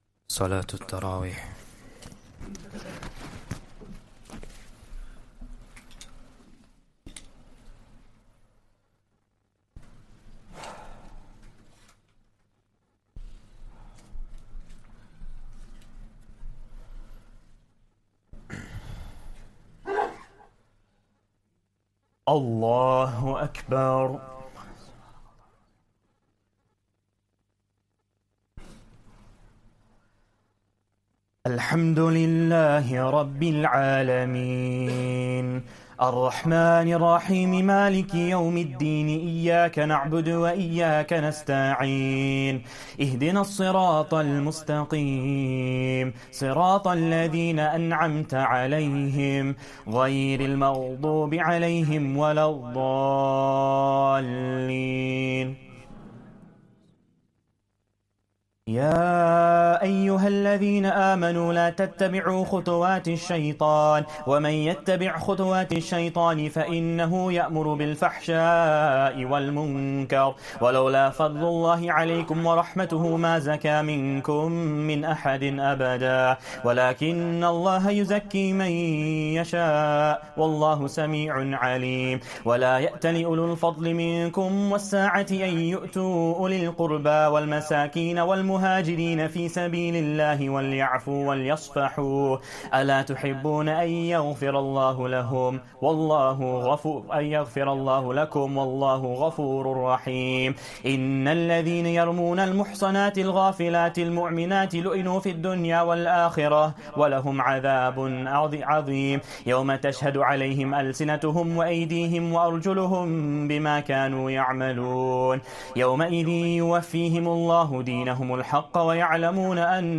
Taraweeh Prayer 15th Ramadan